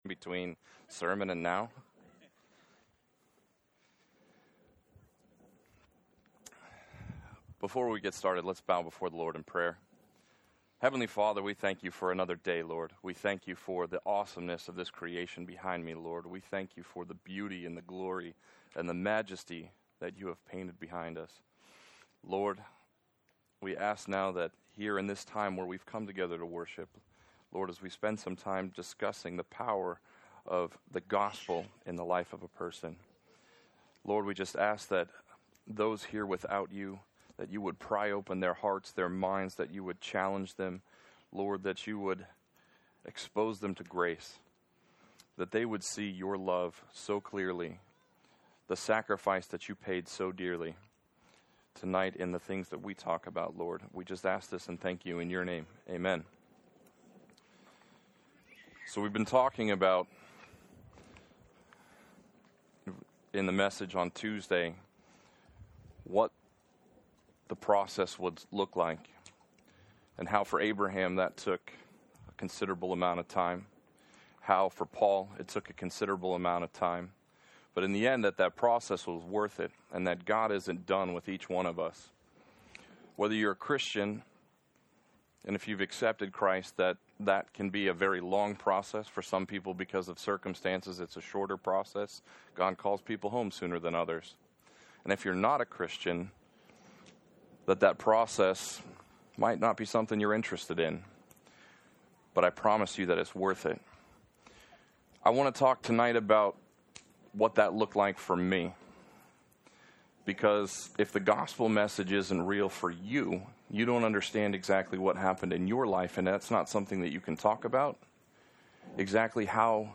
Wednesday Night Campfire